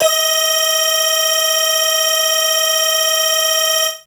55bg-syn20-d#5.wav